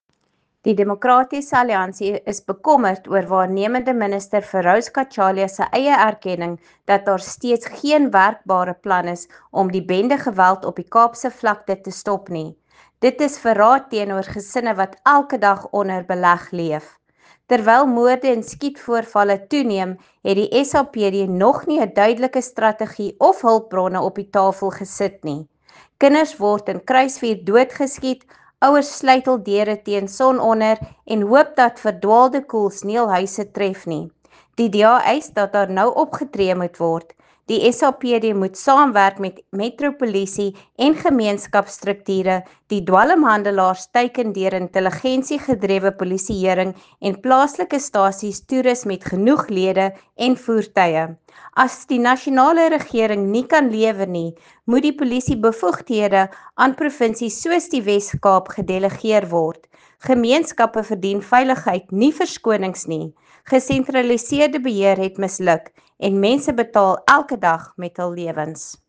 Afrikaans soundbite by Lisa Schickerling MP.